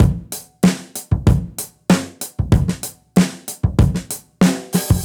Index of /musicradar/dusty-funk-samples/Beats/95bpm